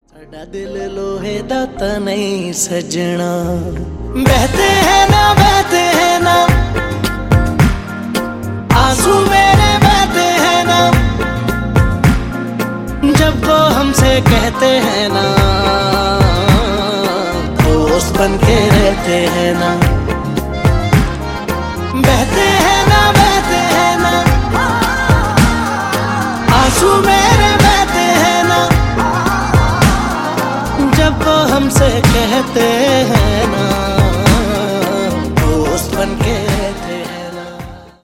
soulful rendition